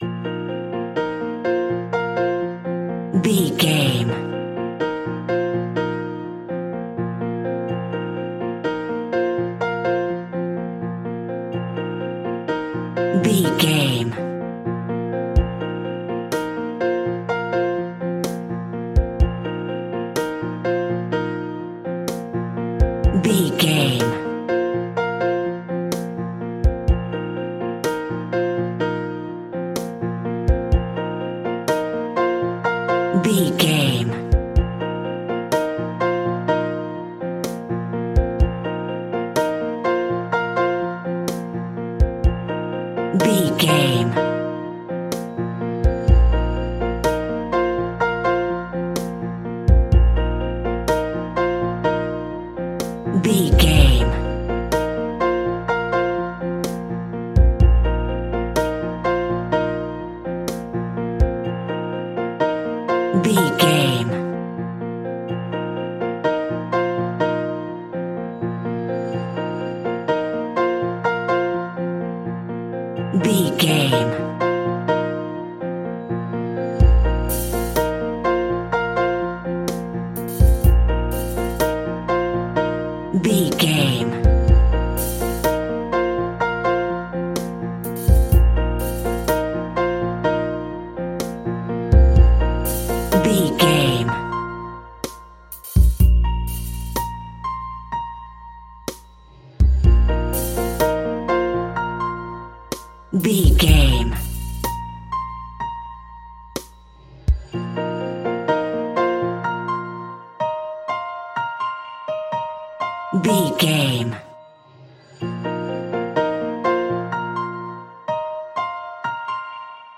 Uplifting
Ionian/Major
B♭
sitar
bongos
sarod
tambura